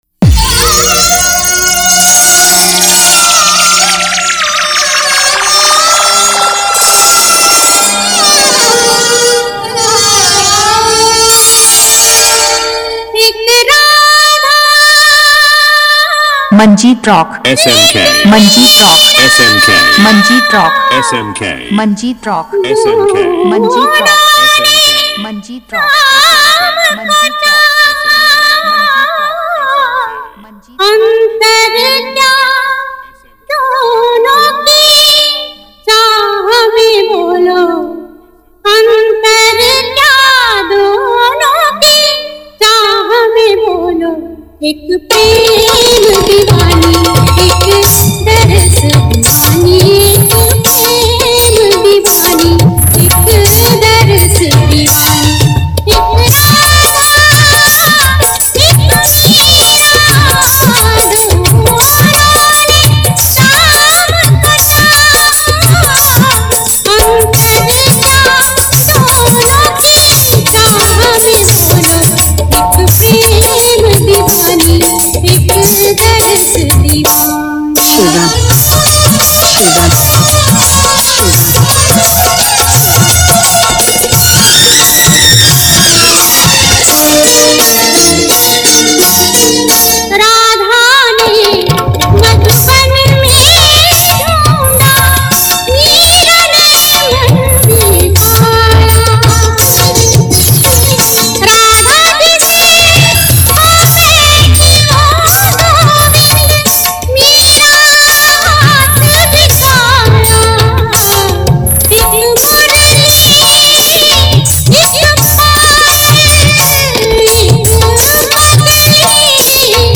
Category : Bhakti Dj Remix Jhanjhan Bass